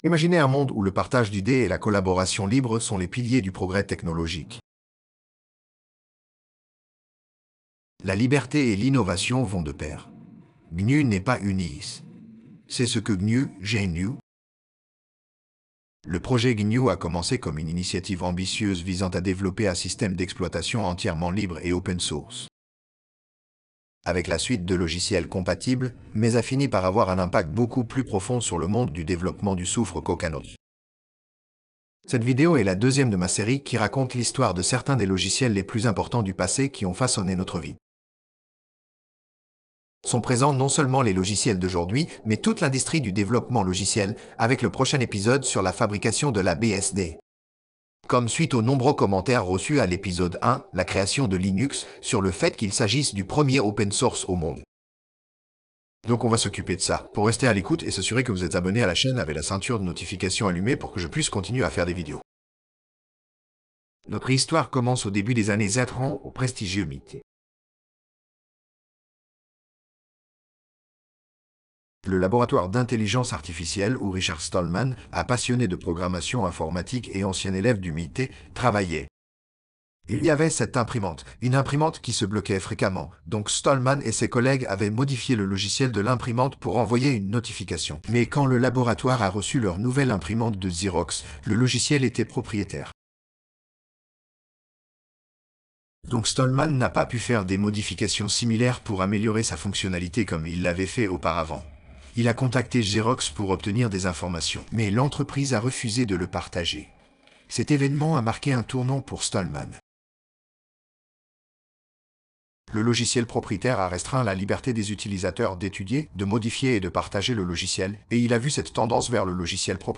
audio_gnu_Vocals_finale.wav